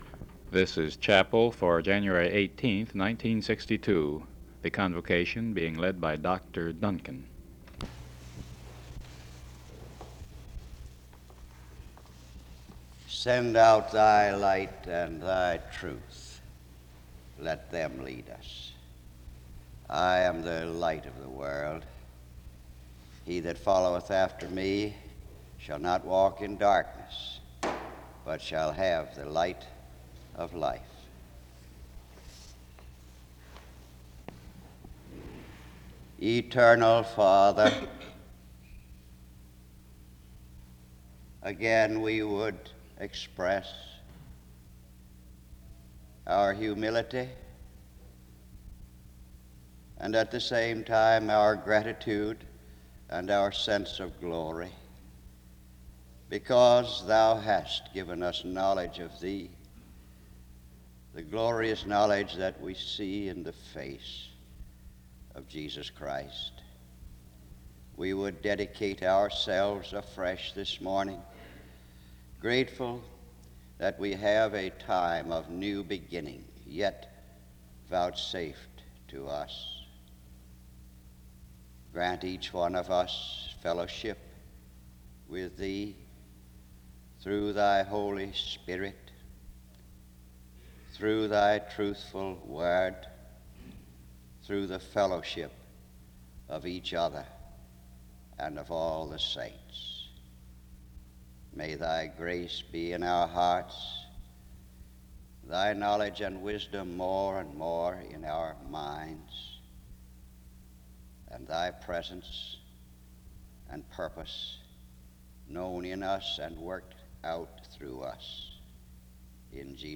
SEBTS Convocation
The service begins with the reading of various Scriptures (00:00-00:35) and prayer (00:36-02:10).
The service ends in prayer (52:11-52:29).